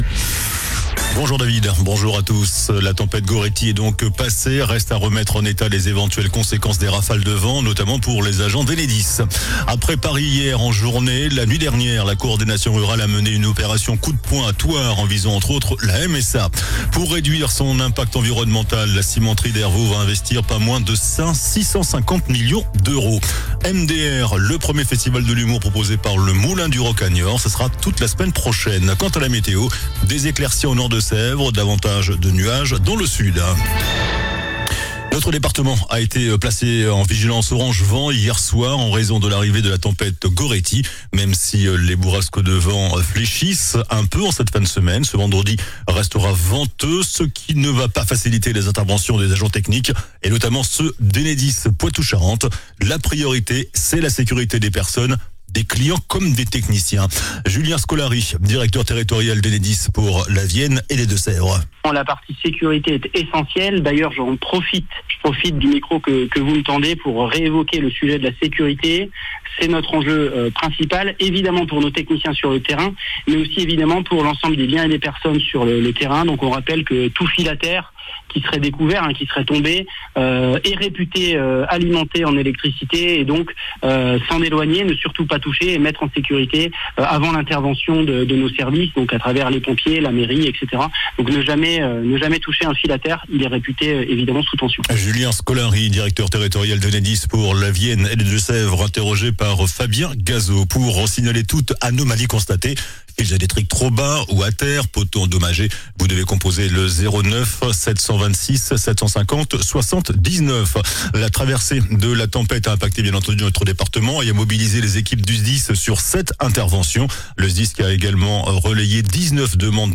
JOURNAL DU VENDREDI 09 JANVIER ( MIDI )